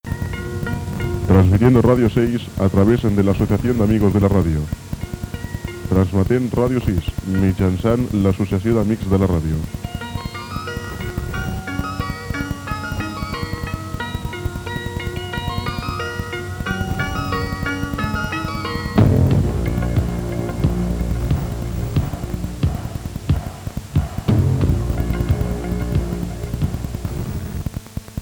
9042a8fce69331bca8288ae47d260cfb16f68d17.mp3 Títol Ràdio 6 Emissora Ràdio 6 Titularitat Tercer sector Tercer sector Cultural Descripció Identificació de l'emissora Data emissió 198?